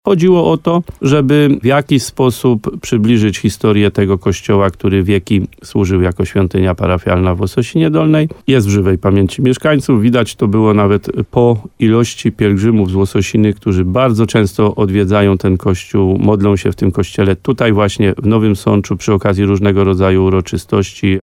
Widać to nawet po ilości pielgrzymów z Łososiny, którzy bardzo często odwiedzają ten kościół w Nowym Sączu, modlą się w nim przy okazji różnego rodzaju uroczystości – mówi wójt gminy Łososina Dolna, Adam Wolak.